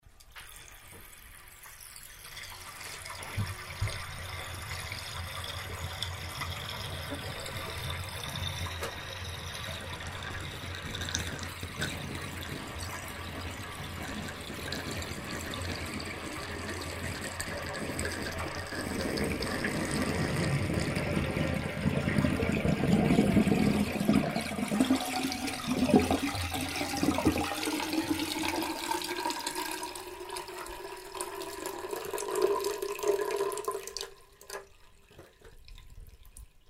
Water Sound Effects
BathEmptying_01.mp3